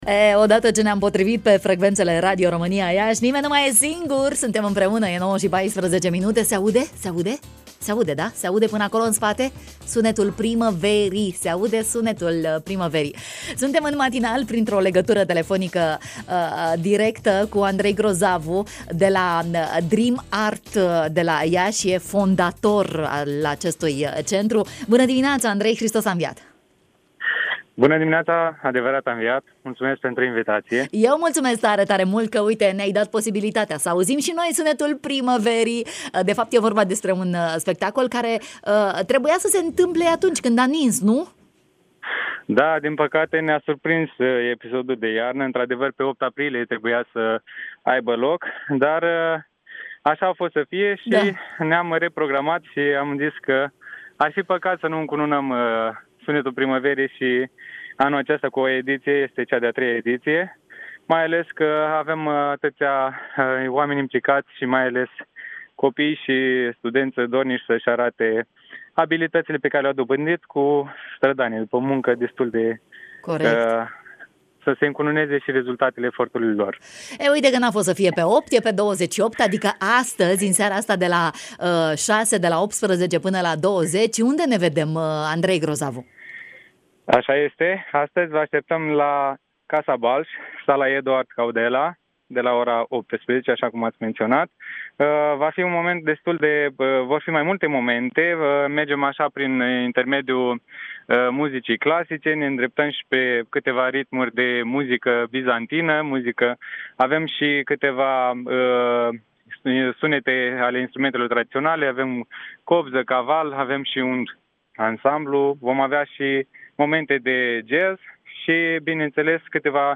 în direct la matinalul de la Radio România Iași